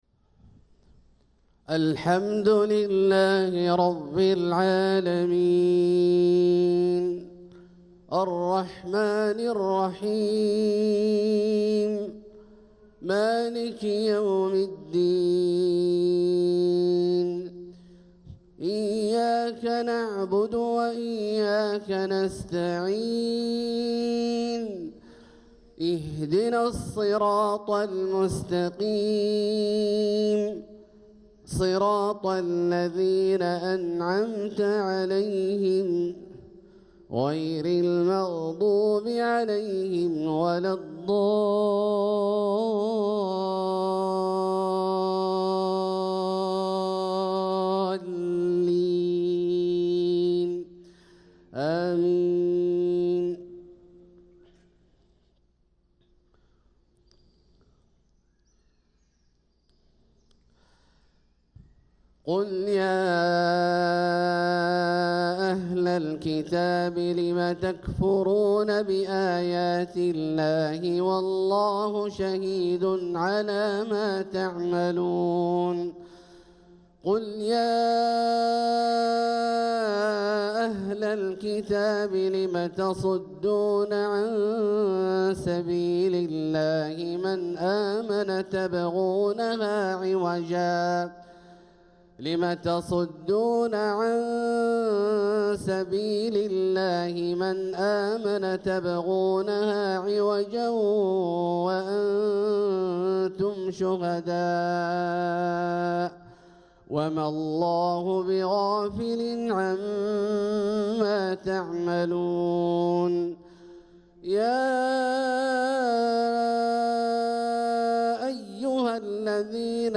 صلاة الفجر للقارئ عبدالله الجهني 23 جمادي الأول 1446 هـ
تِلَاوَات الْحَرَمَيْن .